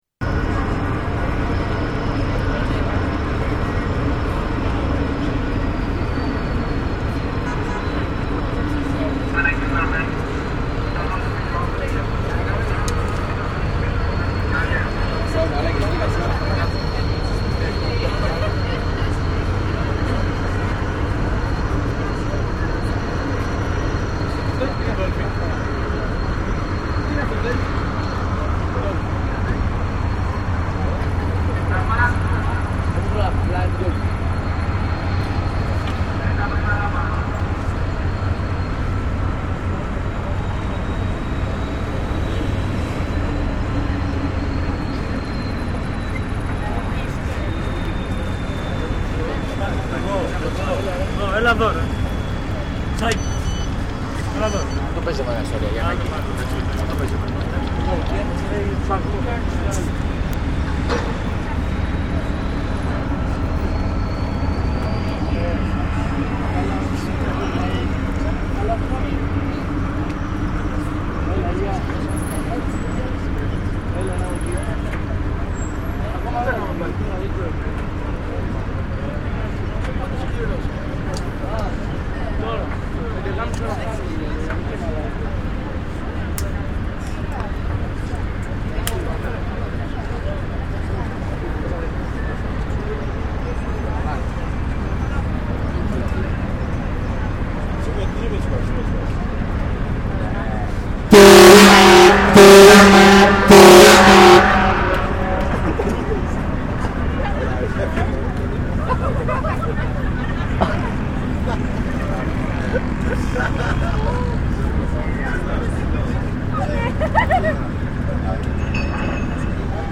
aTHina_04_AEGINA_embarquement.MP3